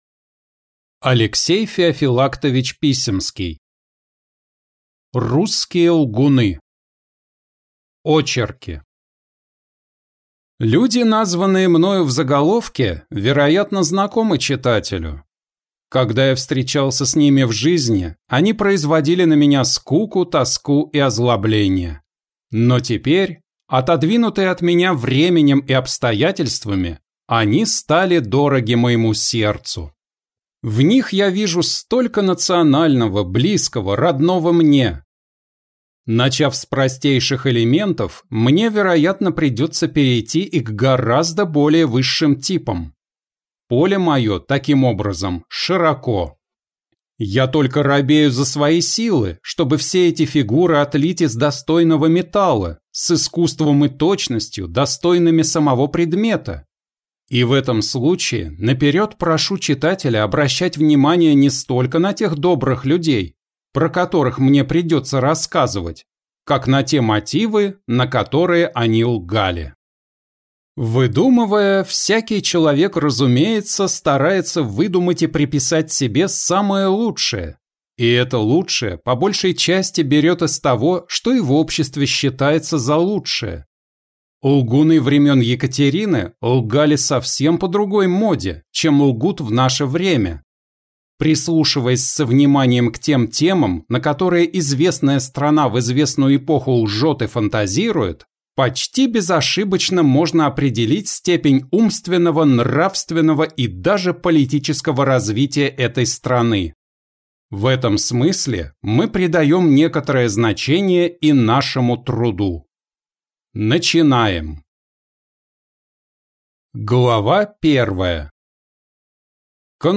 Аудиокнига Русские лгуны | Библиотека аудиокниг